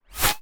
pgs/Assets/Audio/Guns_Weapons/Bullets/bullet_flyby_fast_04.wav
bullet_flyby_fast_04.wav